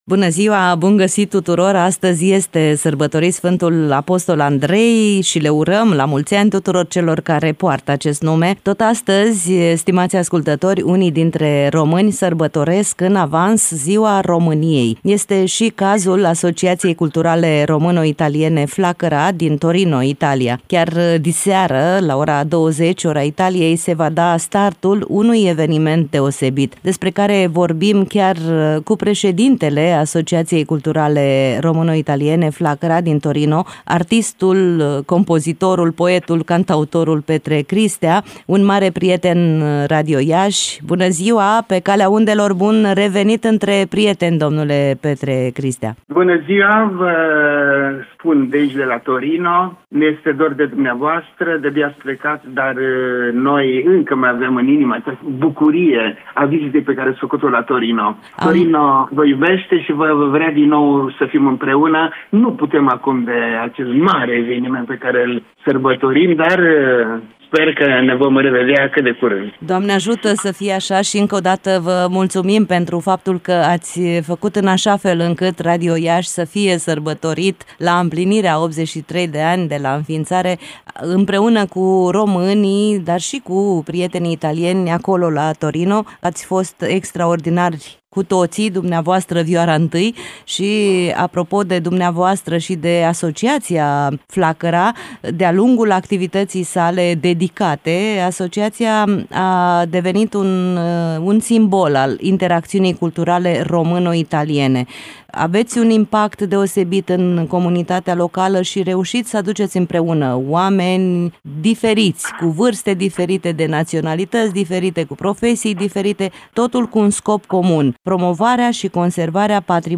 Un interviu difuzat în emisiunea ”Weekend cu prieteni”